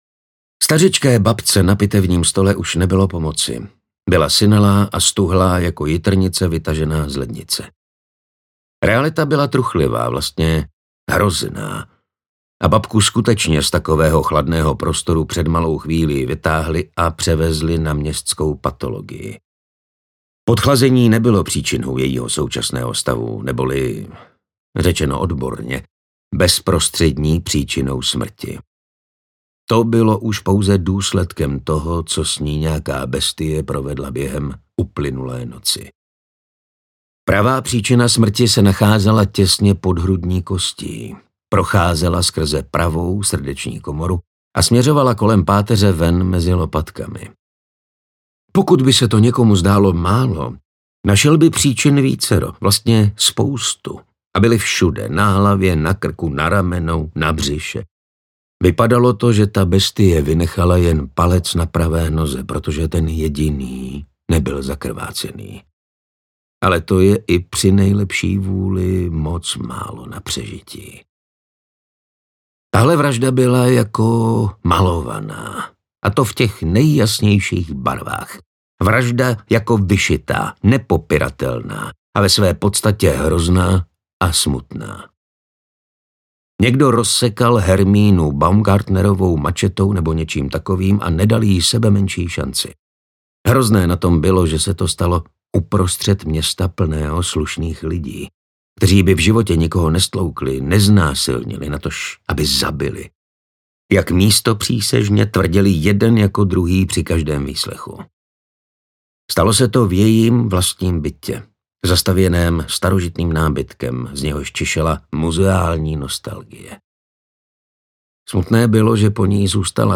Popel všechny zarovná audiokniha
Ukázka z knihy